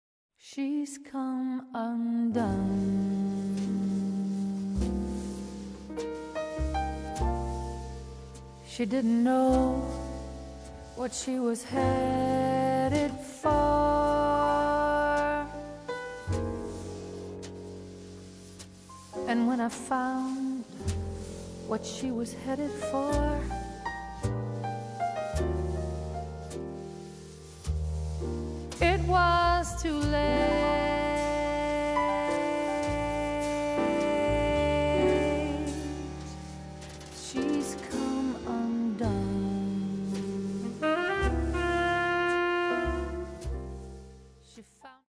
vocals
piano
saxes
bass
drums
guitar
and straight-ahead jazz still create musical magic.